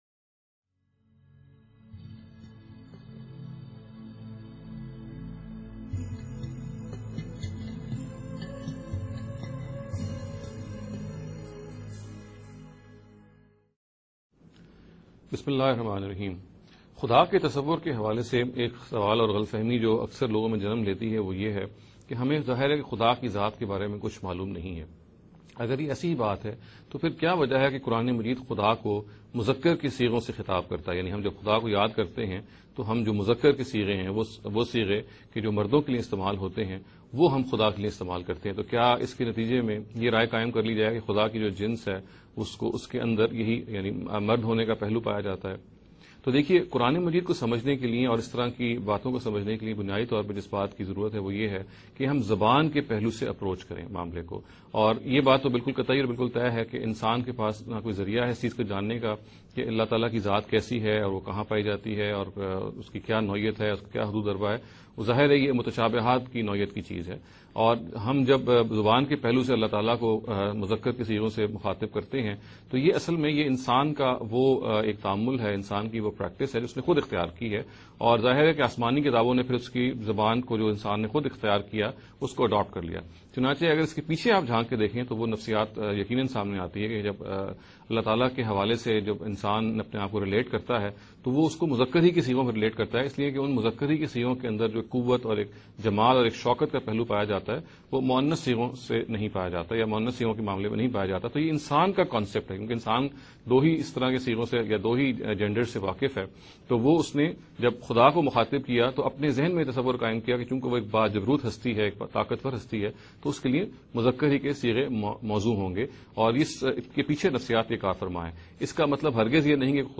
an Urdu lecture series